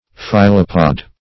Search Result for " phyllopod" : The Collaborative International Dictionary of English v.0.48: phyllopod \phyl"lo*pod\ (f[i^]l"l[-o]*p[o^]d), n. (Zool.)